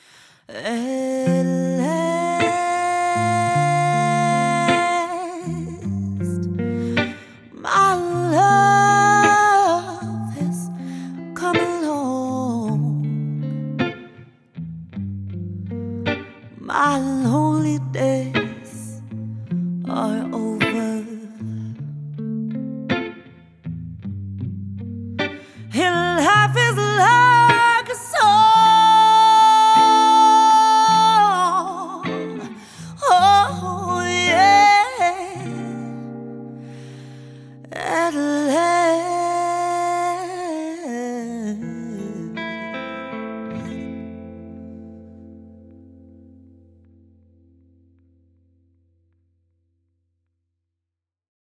a chic soul band with exceptional vocals and musicianship.